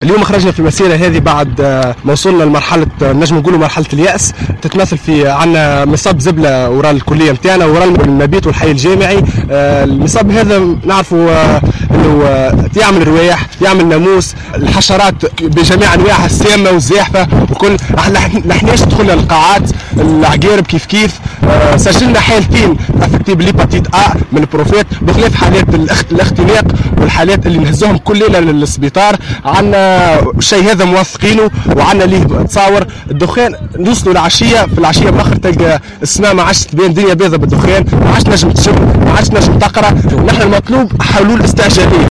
أحد الطلبة